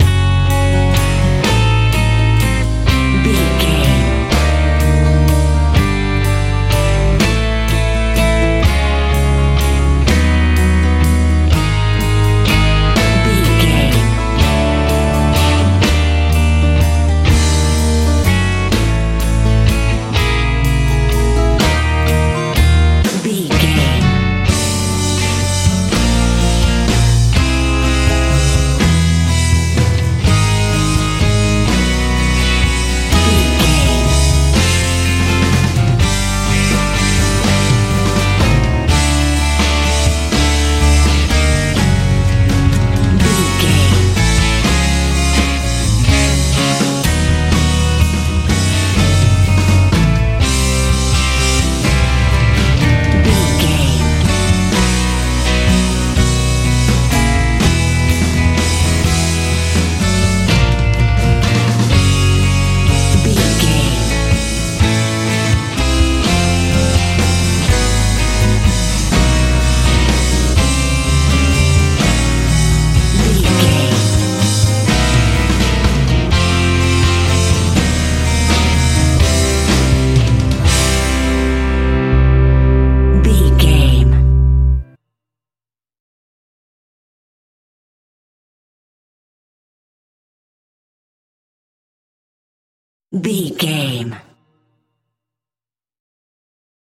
med rock feel
Ionian/Major
F♯
magical
mystical
electric guitar
acoustic guitar
bass guitar
drums
80s
strange
suspense